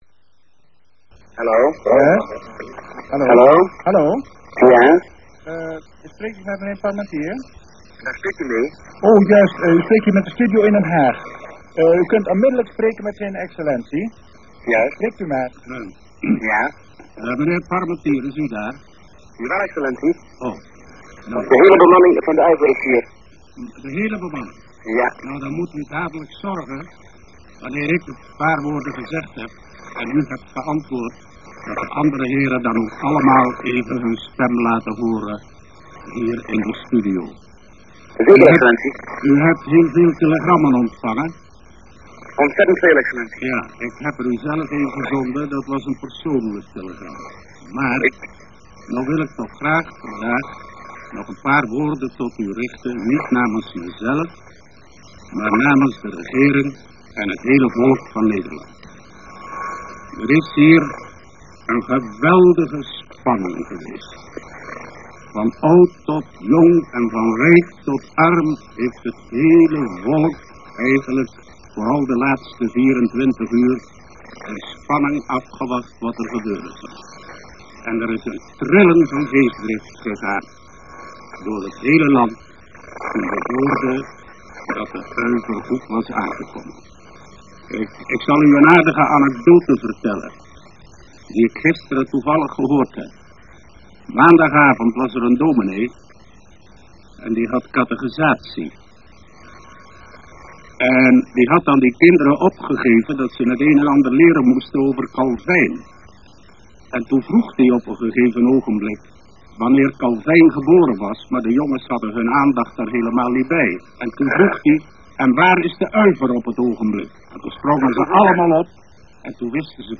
Click HERE if you have RealPlayer (audio) version 5 or 6 to hear a 1934 short-wave telephone link between Australia (Melbourne) and the Netherlands (the Haque). The prime-minister of the Netherlands is talking to the crew of the Uiver passenger plane (a DC-2) which arrived in Melbourne as second (but as first passenger plane) in the Europe to Melbourne air race.
The same sound file (13 minutes, Mpeg, 1.4 Mb .mp2) but with limited quality HERE